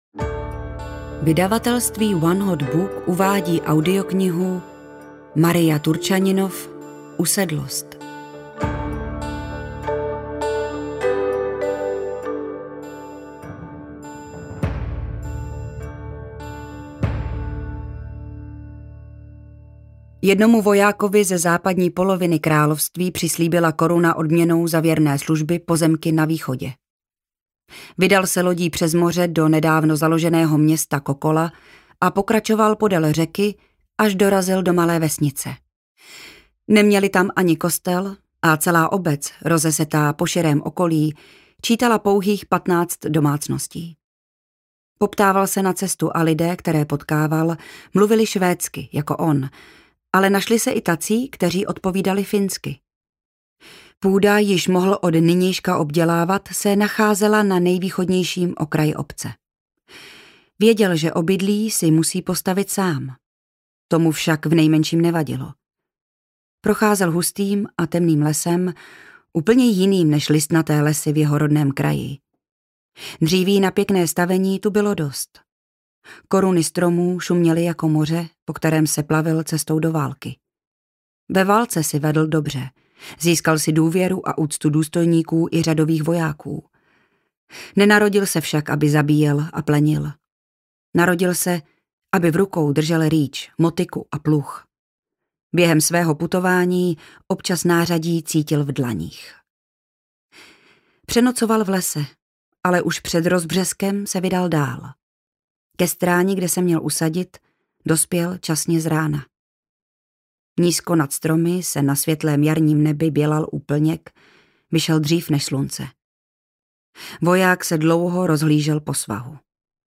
Usedlost audiokniha
Ukázka z knihy